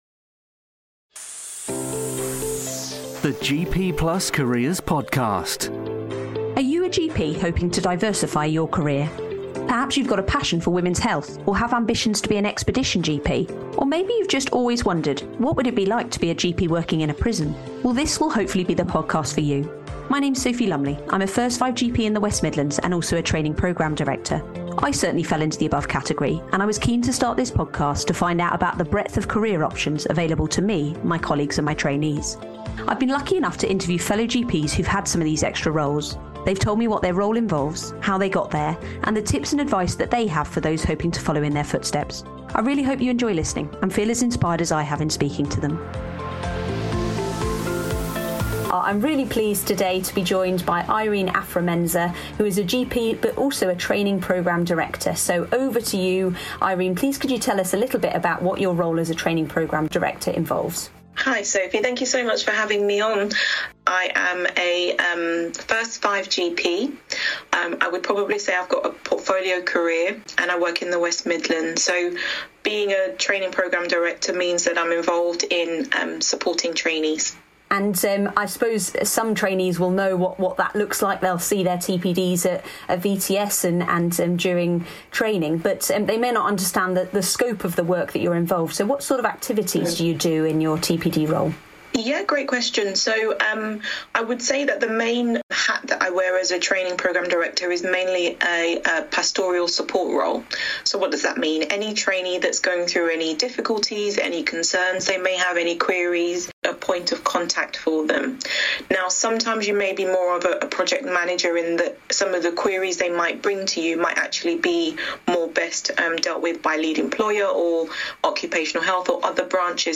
interviews GPs who do their GP work alongside an extended role, special interest or extra responsibility. Each podcast is focused on a specific interest or role even if the GP has multiple.